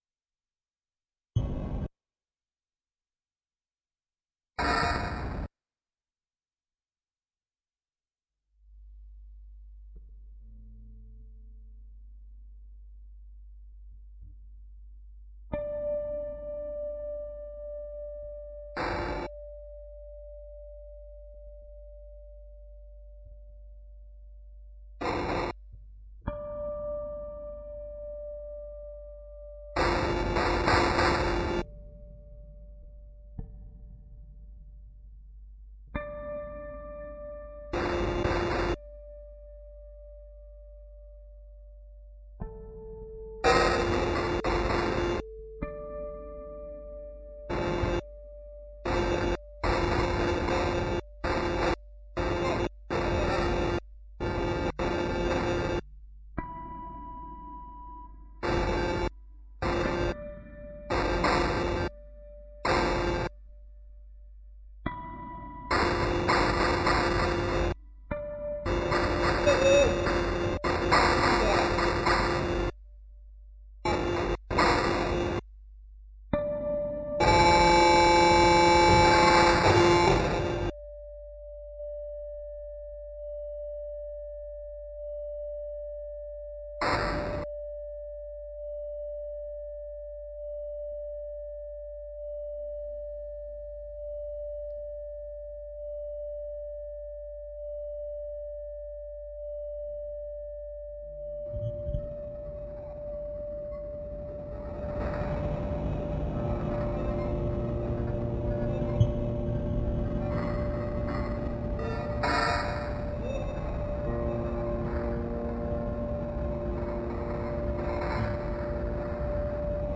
Electroacoustic duo
an experimental musician of galician origins.